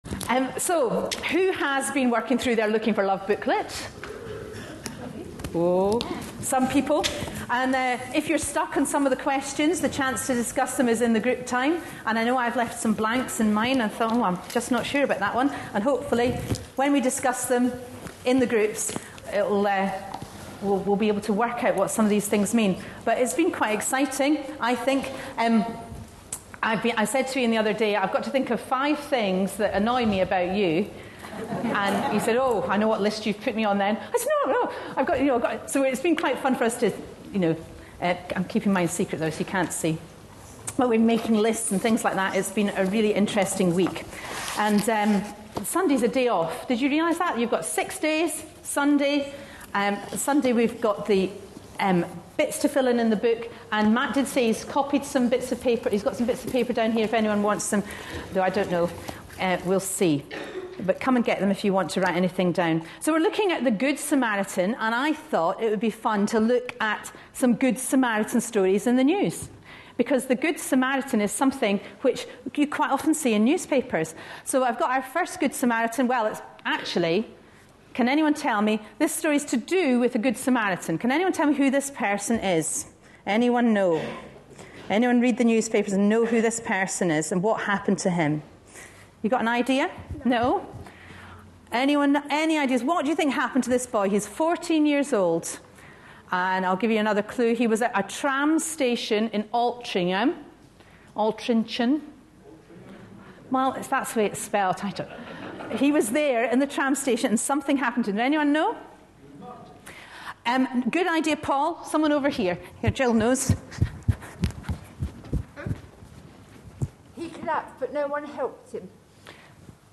A sermon preached on 19th February, 2012, as part of our Looking For Love (10am Series) series.